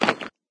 stone.ogg